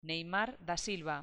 NEYMAR da SilvaNeimár da Sílva